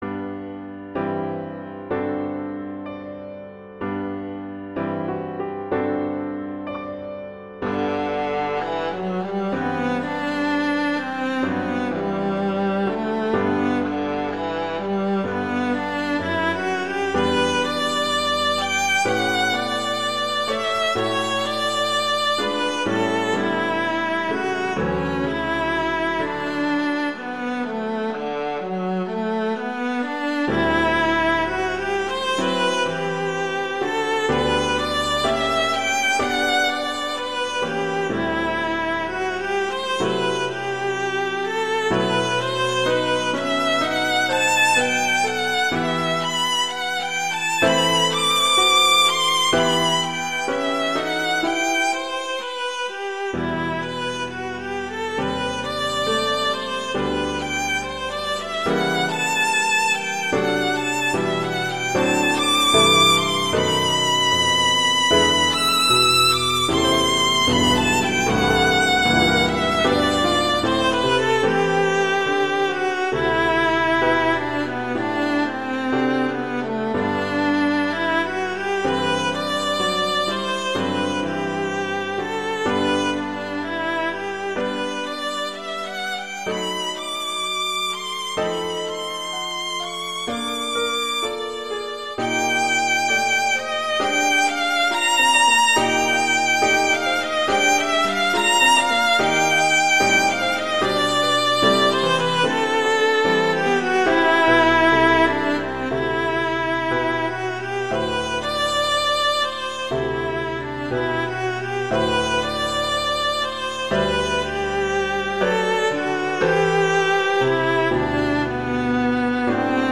classical
G major
♩=63 BPM